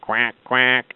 quackquack.mp3